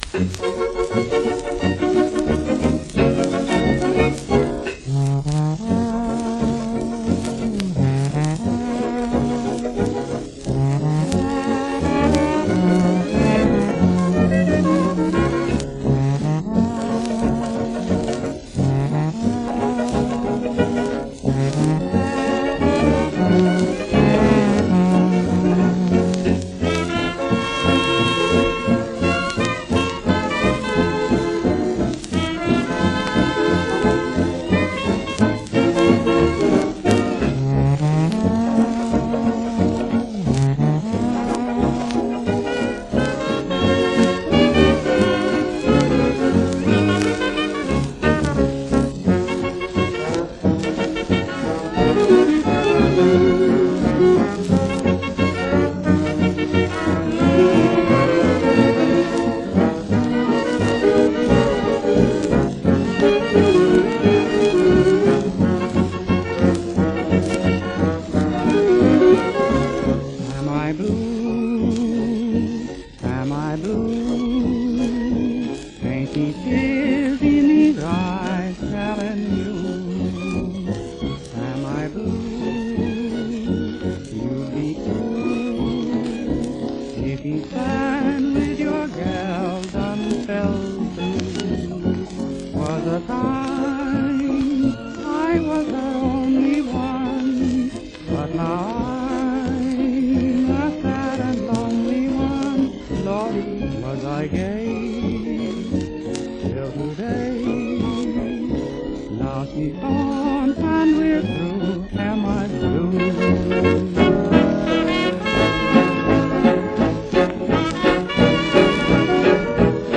A couple of sad love songs today.